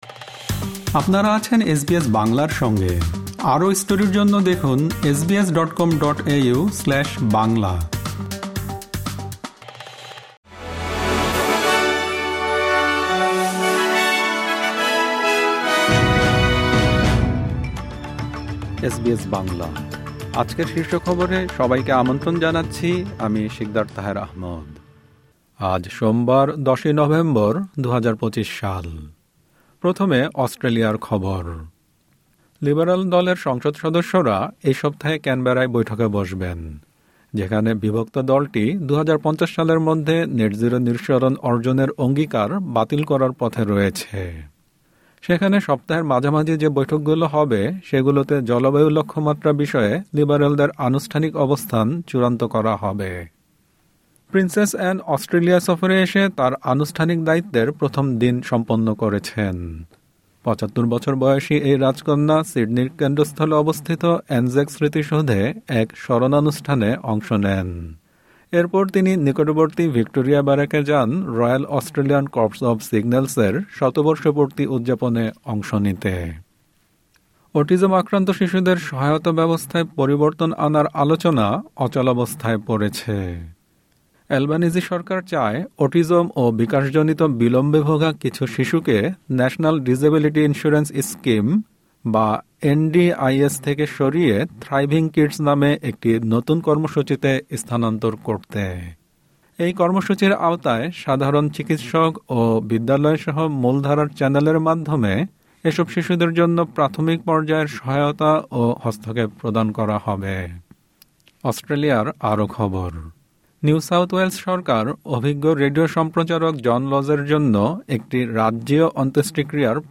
এসবিএস বাংলা শীর্ষ খবর: ১০ নভেম্বর, ২০২৫